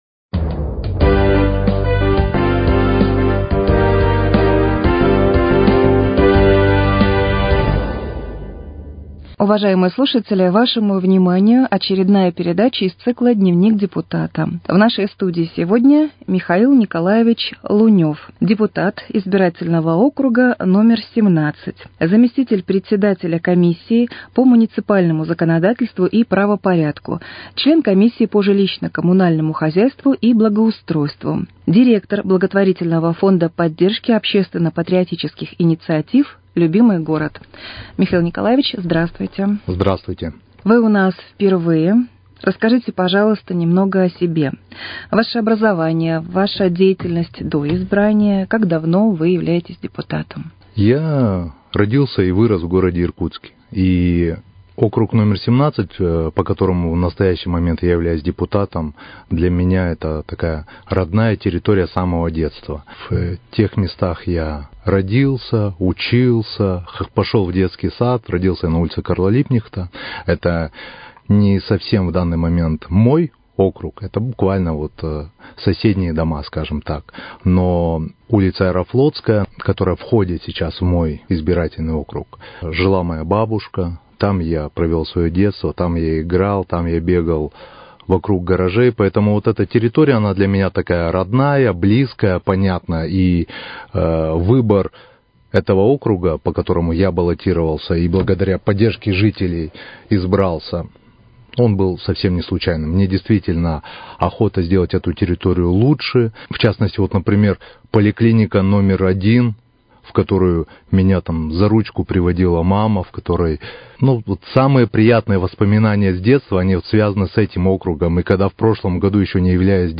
С депутатом Думы Иркутска по 17 избирательному округу Михаилом Луневым беседует